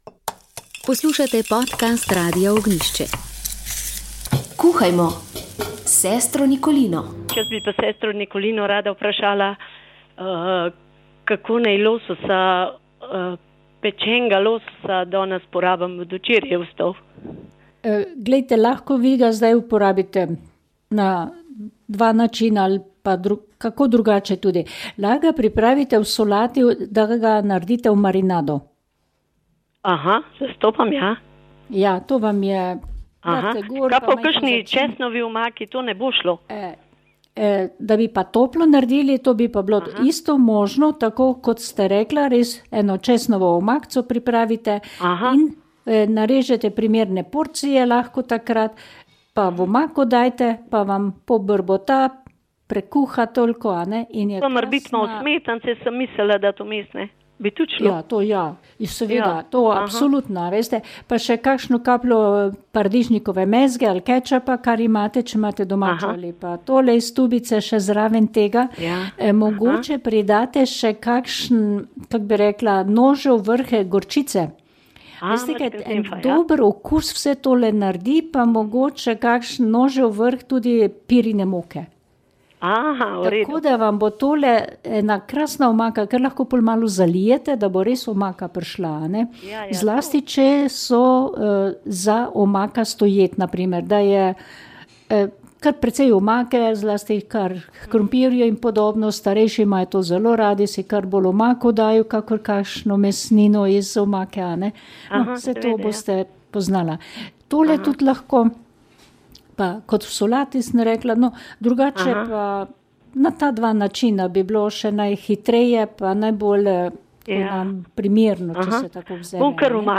Molitev je vodil ljubljanski pomožni škof msgr. Franc Šuštar.